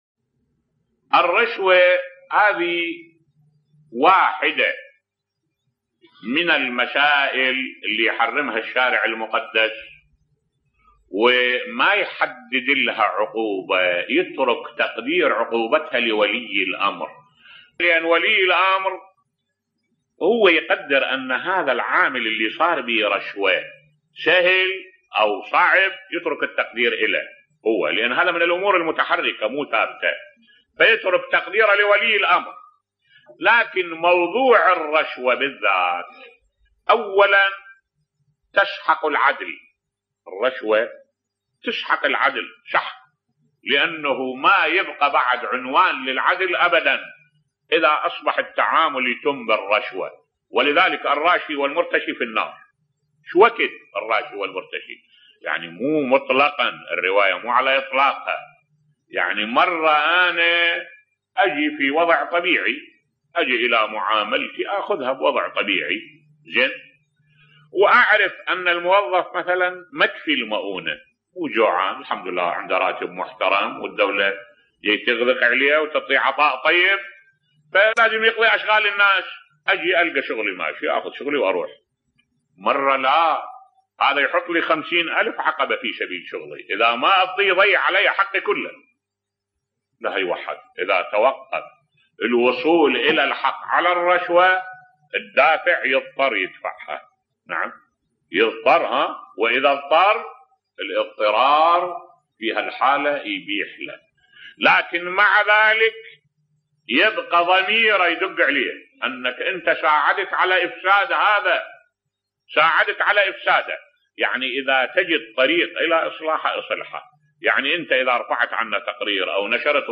ملف صوتی الرشوة وآثارها على المجتمع بصوت الشيخ الدكتور أحمد الوائلي